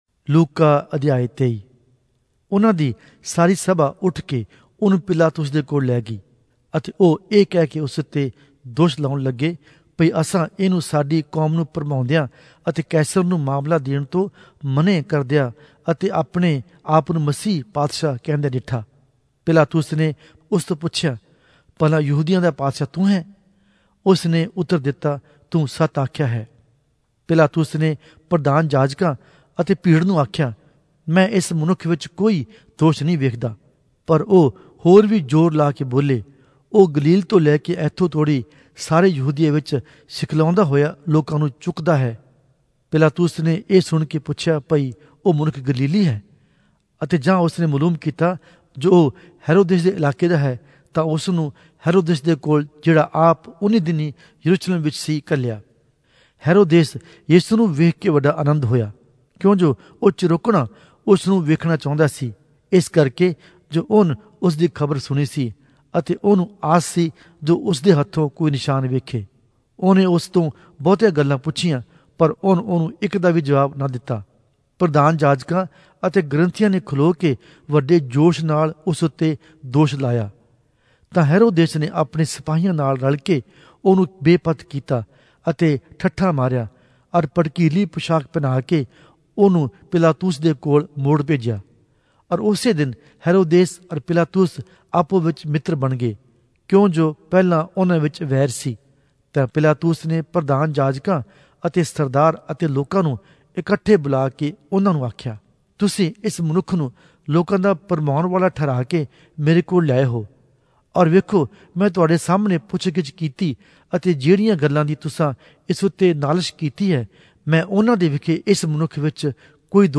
Punjabi Audio Bible - Luke 9 in Mkjv bible version